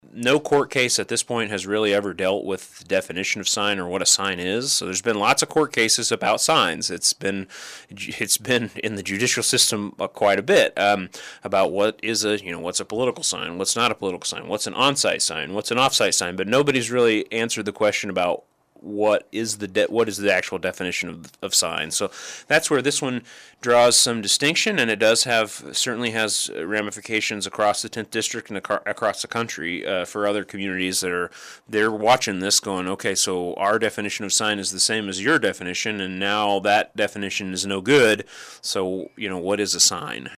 City Manager Jacob Wood joined in on the KSAL Morning News Extra with a look at the case that may have far reaching impacts on other municipalities.